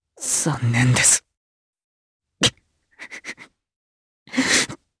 Lucias-Vox_Sad_jp.wav